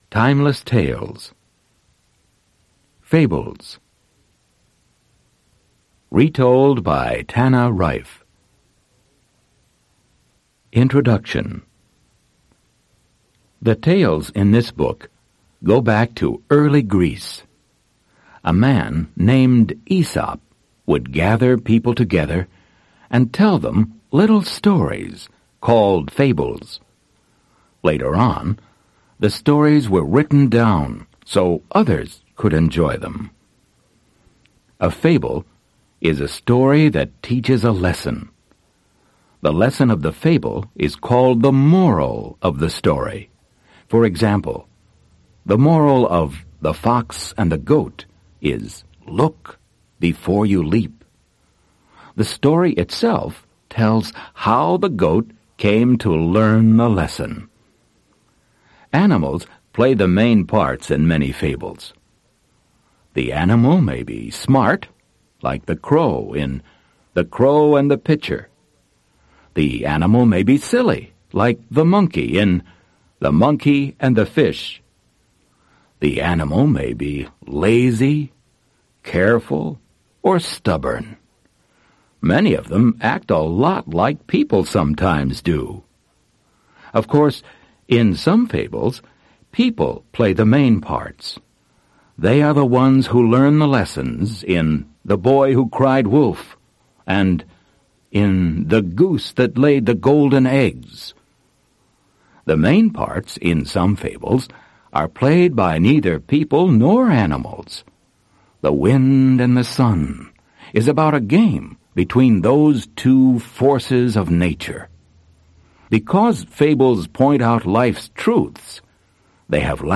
Аудиокнига Басни. Fables | Библиотека аудиокниг